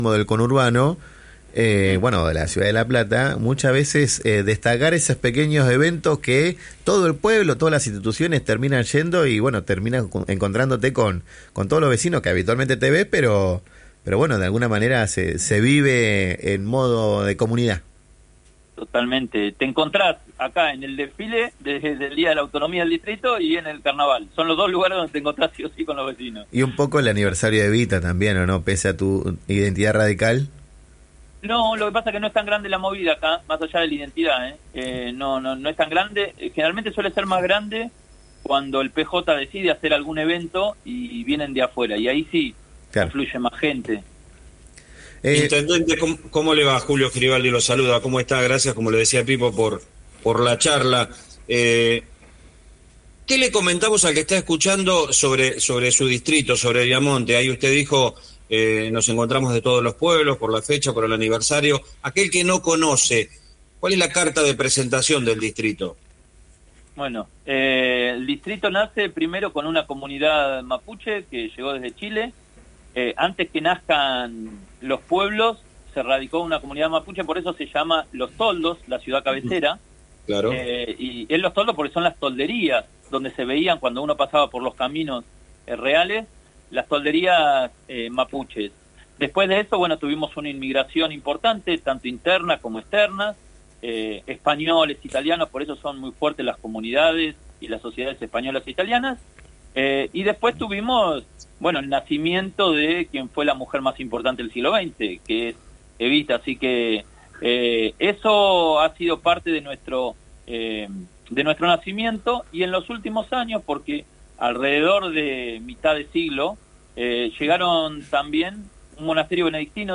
Franco Flexas fue entrevistado en el programa “Diagonal a Contramano” que se emite por FM 90.9 Radio La Plata.